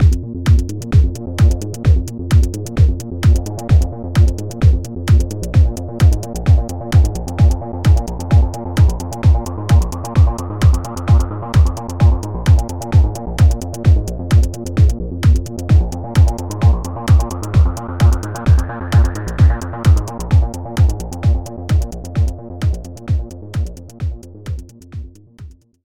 Techno Contest 2021
Hört sich an wie Turrican auf Koks :right: